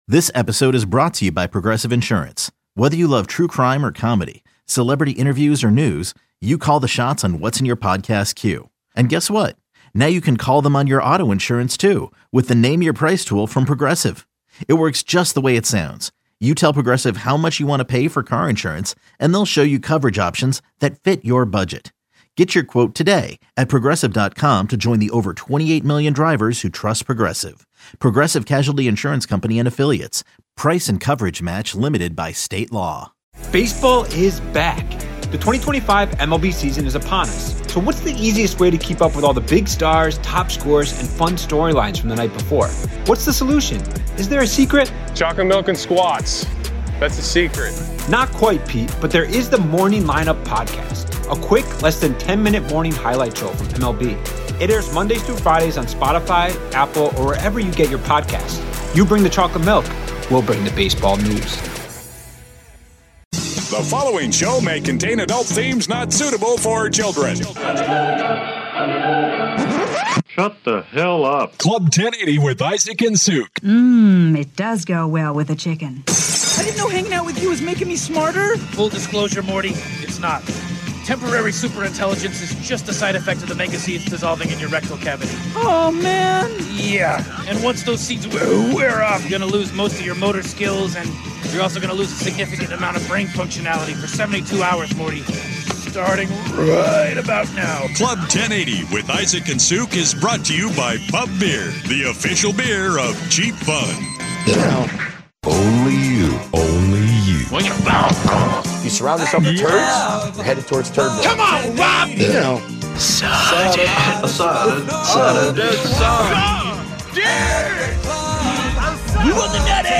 Portland's iconic sports talk show.